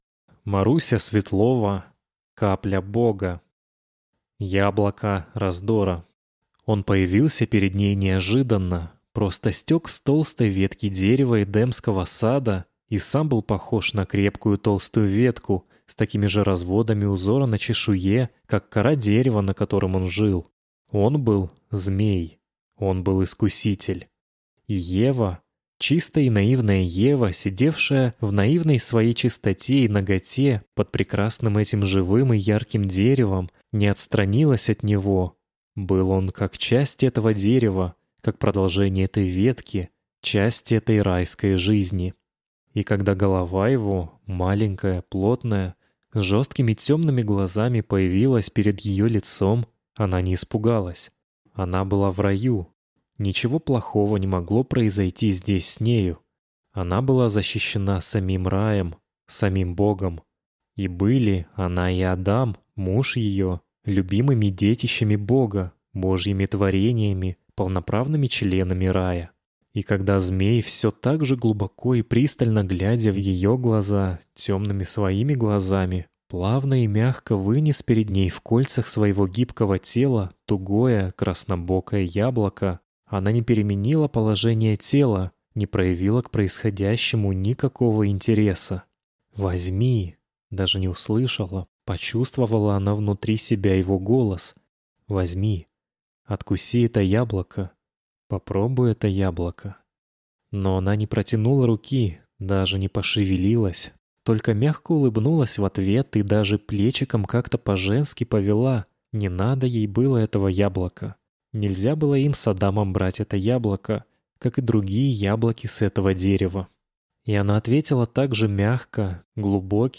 Аудиокнига Капля бога (сборник) | Библиотека аудиокниг